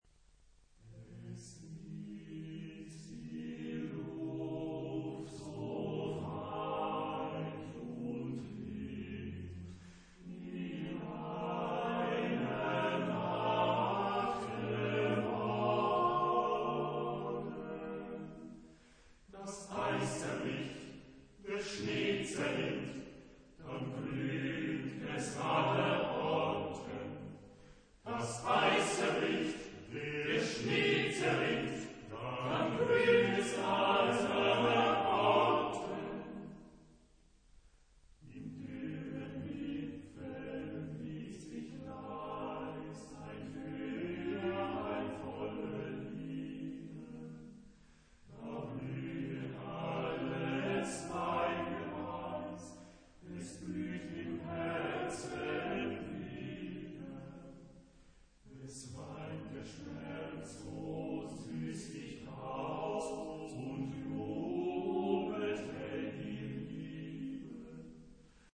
in Weltliche Musik für Männerchor à cappella
Epoque: 19th century
Genre-Style-Form: Romantic ; Secular
Type of Choir: TTBB  (4 men voices )
Tonality: E flat major